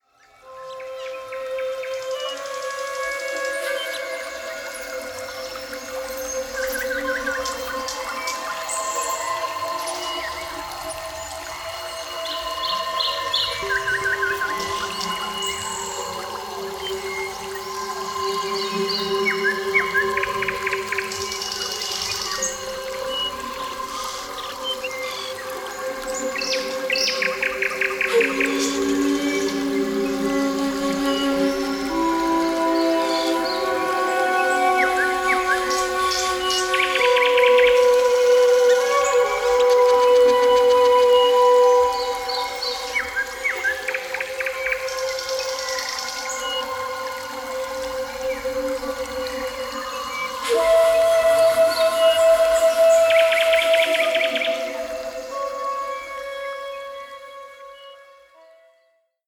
Mit sanfter Musik untermalte Naturgeräusche.